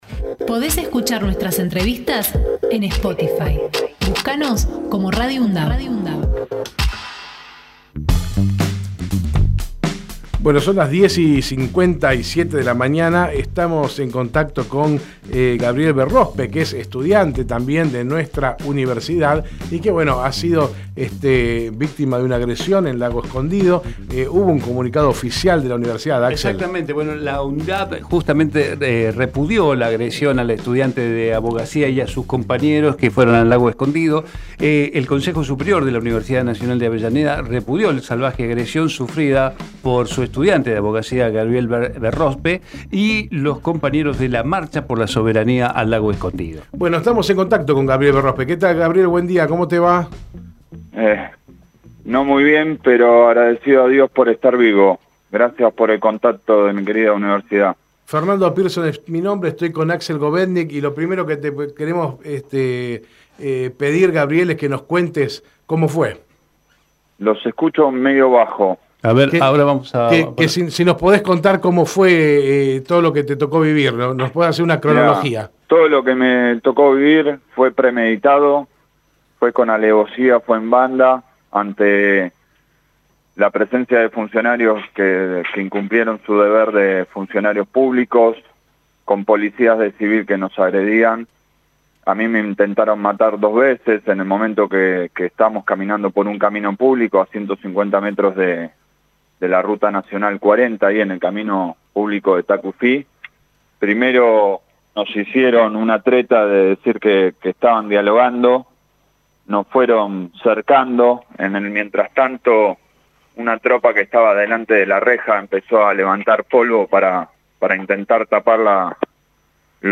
Gabriel Berrozpe en Hacemos PyE Texto de la nota: Compartimos la entrevista realizada en Hacemos PyE a Gabriel Berrozpe, el exconcejal de Quilmes y estudiante de Abogacía de la UNDAV . Conversamos sobre la séptima marcha por la soberanía de Lago Escondido (Río Negro) donde junto a un grupo de ocho personas fue atacado por parte de una patota que les impidió ingresar al lugar donde se ubica la estancia de Joe Lewis.